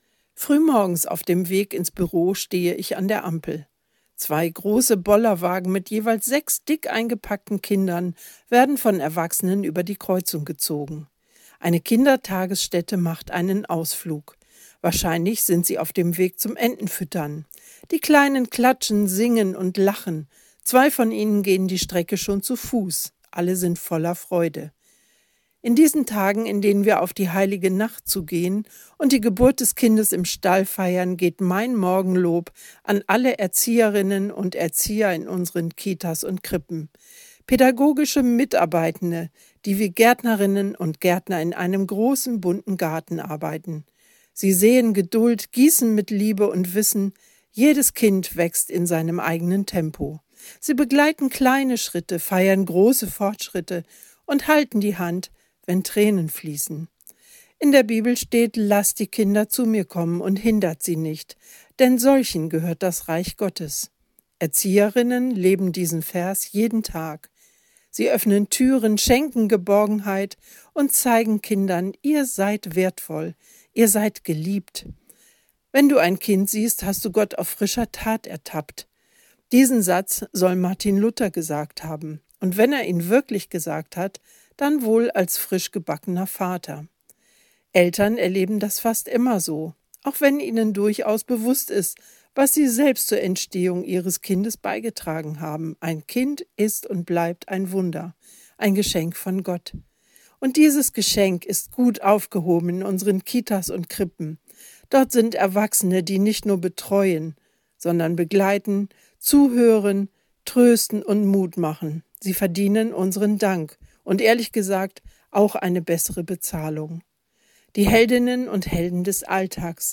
Radioandacht vom 17. Dezember